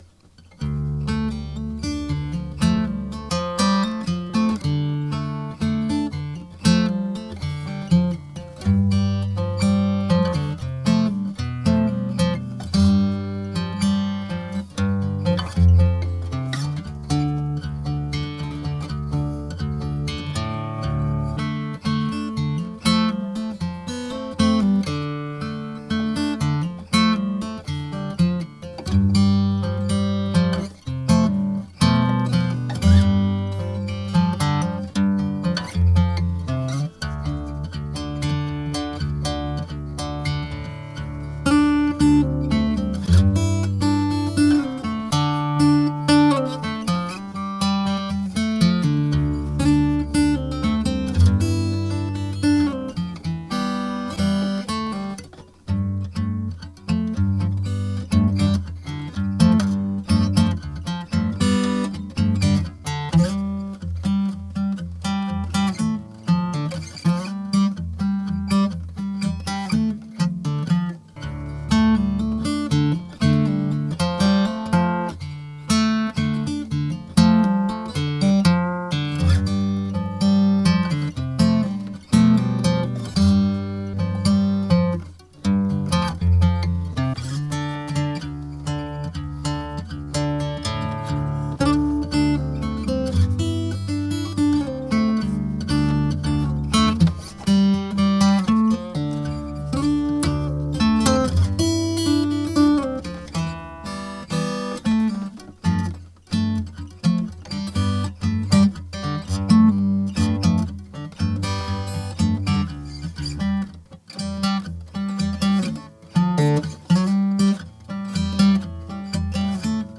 Instrumentals for Guitar